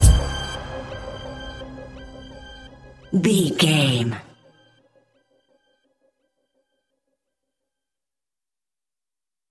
Aeolian/Minor
D
drum machine
synthesiser
hip hop
soul
Funk
neo soul
acid jazz
energetic
bouncy
funky
hard hitting